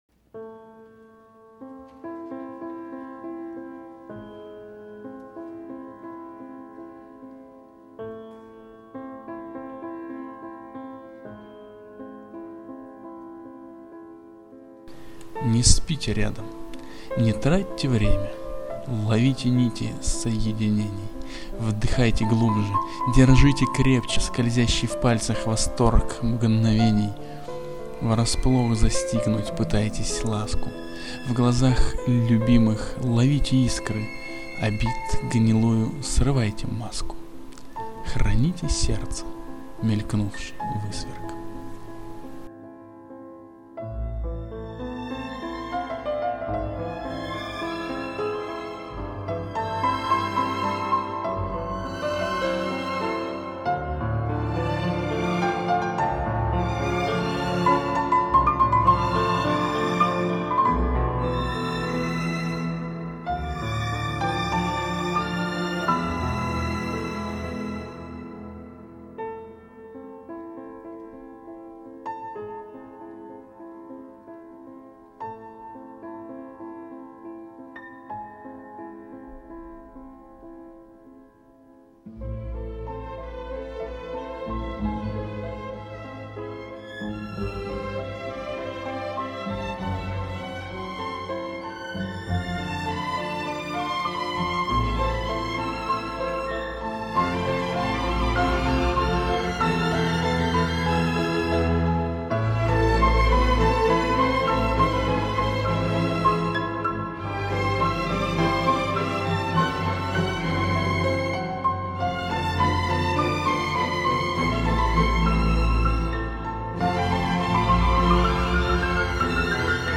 очень понравилось,спасибо,за стих,чтение и,конечно,музыку. smile
Музычка эта - Збигнев Прайснер "Love Story", саундтрек из Порывов Желания(Mouvements du Desir).